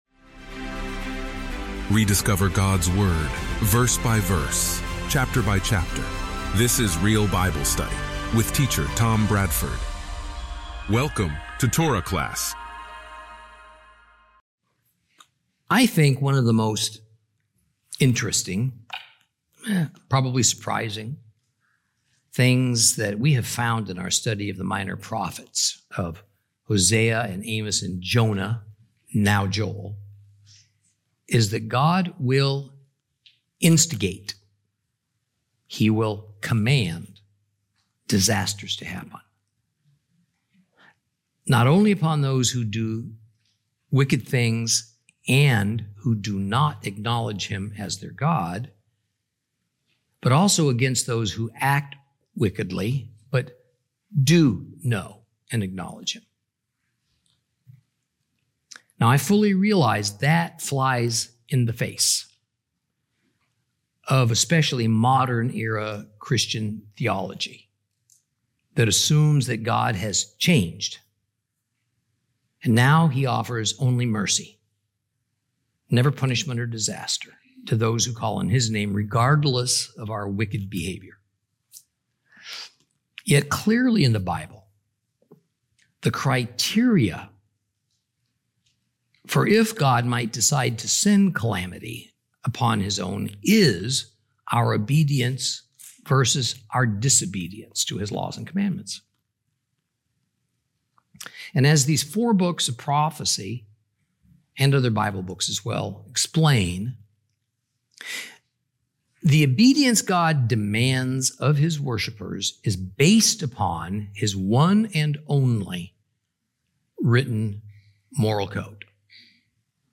Teaching from the book of Joel, Lesson 2 Chapter 1 continued.